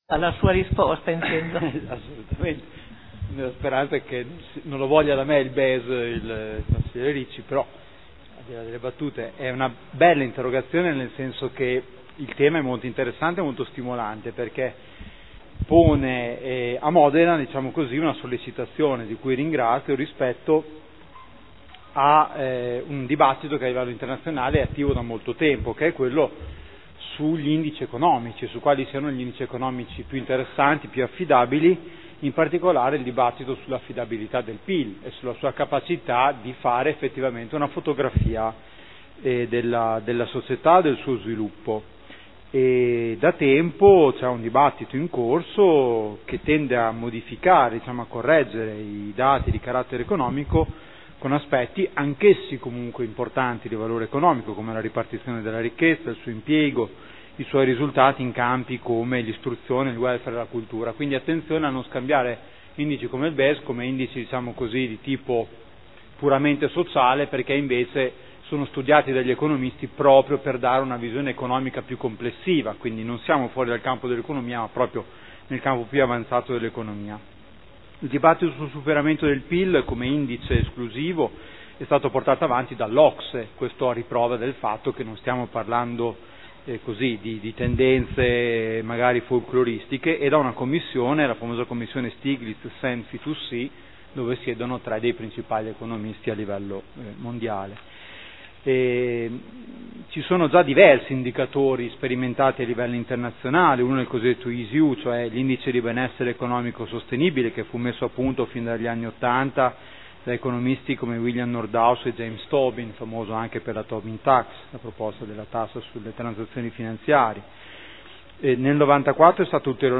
Seduta del 10/06/2013 Risponde a Interrogazione del consigliere Ricci (SEL) avente per oggetto: “Dam un BES”